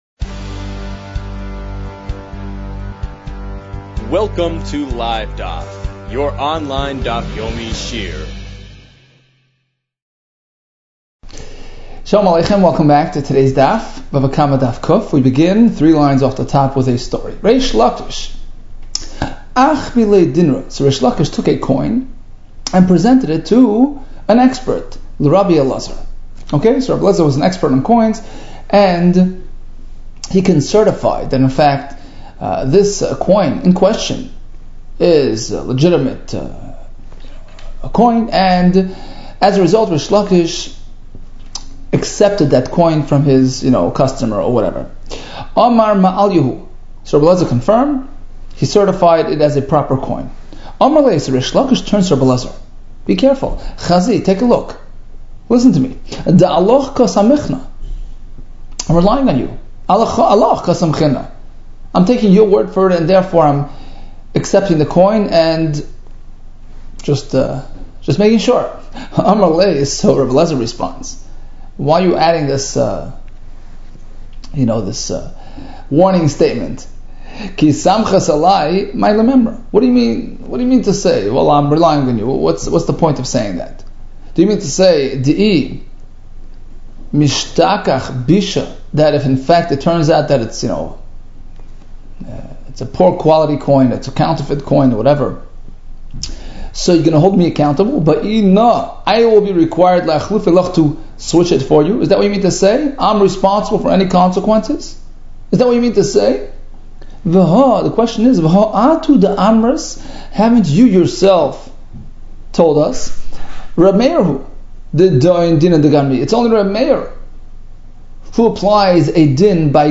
Bava Kamma 99 - בבא קמא צט | Daf Yomi Online Shiur | Livedaf